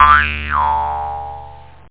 JAWHARP.mp3